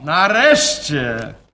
Worms speechbanks
drop.wav